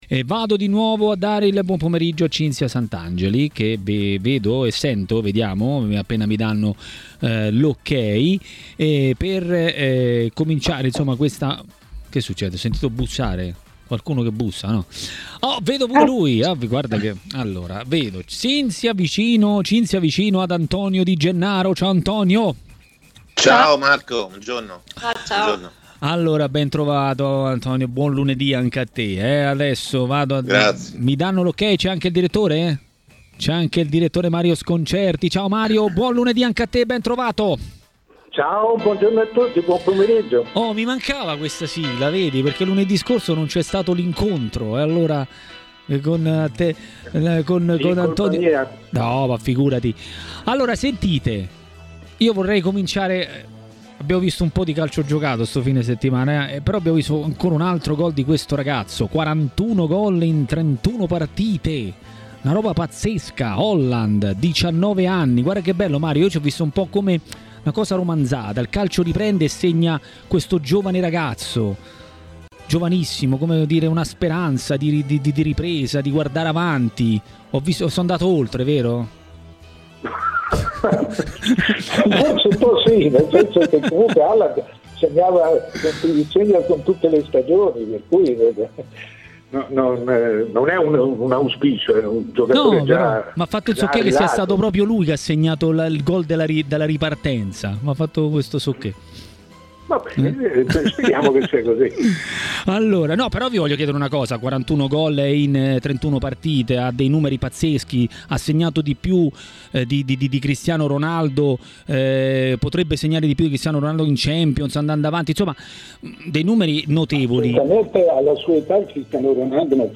Antonio Di Gennaro, ex calciatore e opinionista, ha parlato dei temi di giornata nel corso di Maracanà, trasmissione di TMW Radio.